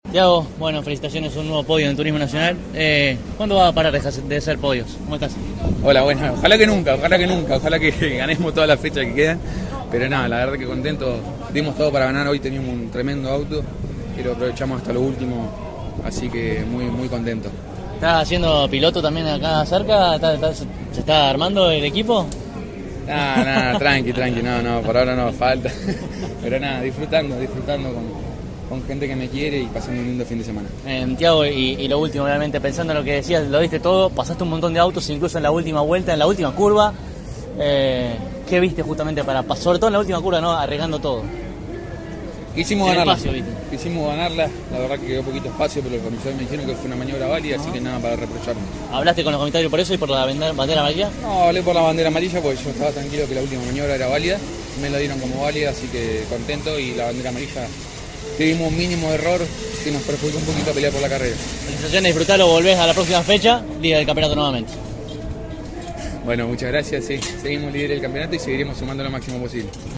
Estas son todas las entrevistas: